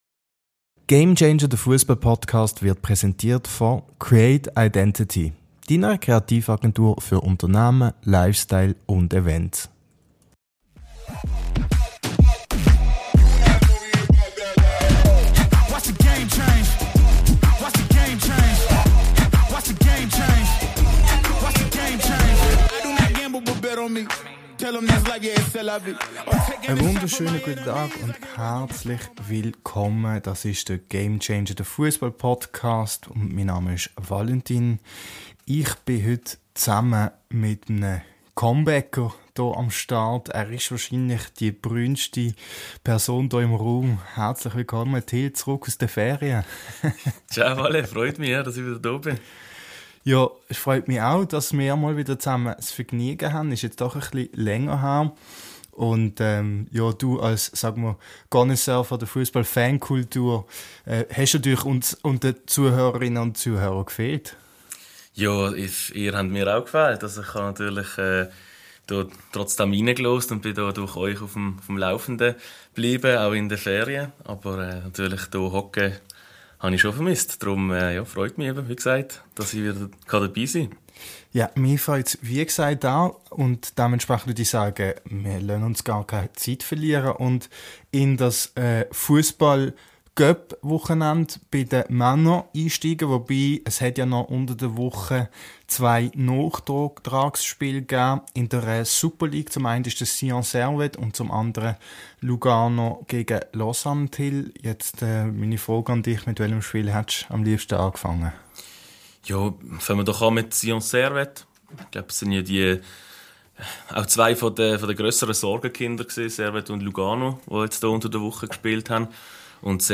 Viel Spass beim Hören, auch wenn wir uns an dieser Stelle für gewisse Tonschwierigkeiten entschuldigen müssen.